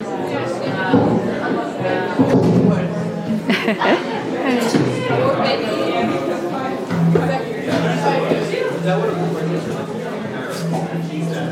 Room_noise3.mp3